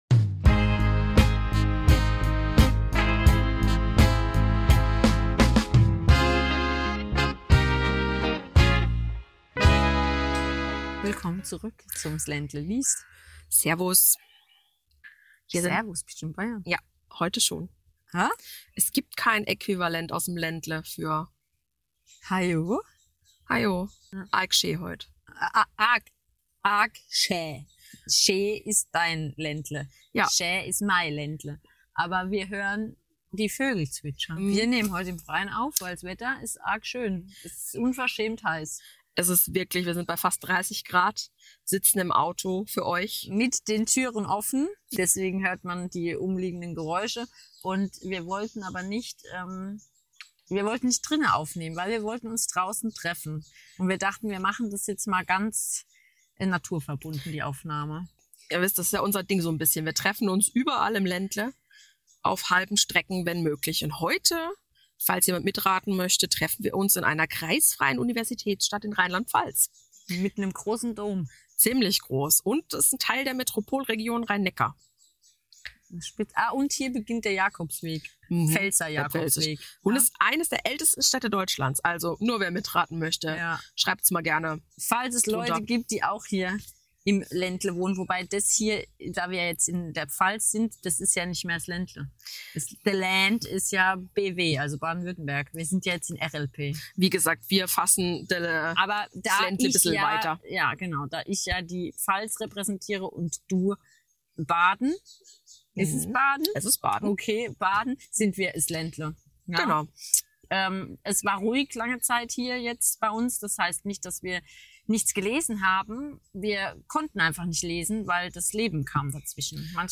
Ebenso wird es verschiedene Buchempfehlungen geben und in der Natur viel gelacht.